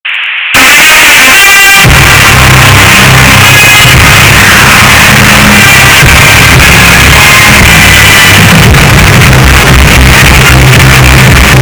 Bass Boosted Nfl Badly